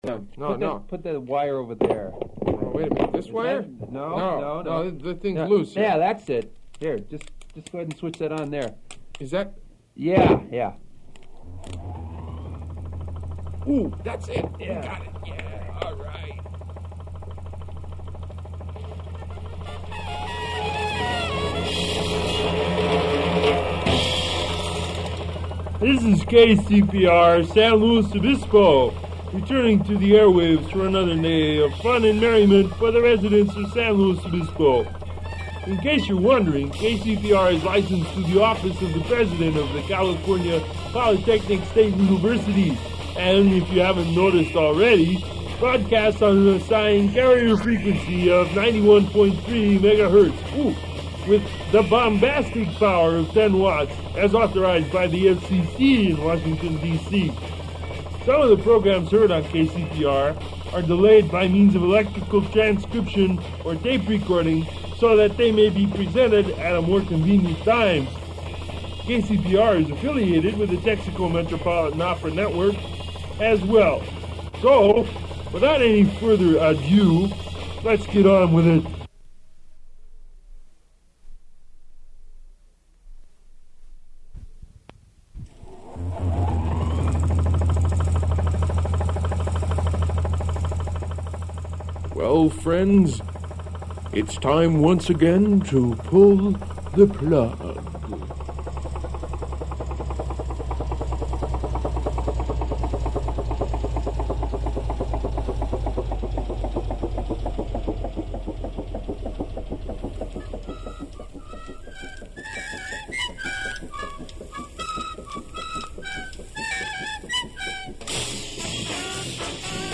[00:00:00] Jingle and information about KCPR's broadcasting
2000 Watt sign-on advertisement in the style of Frankenstein/Dracula
Bluegrass style jingle
Air raid siren jingle for new stereo signal
Open reel audiotape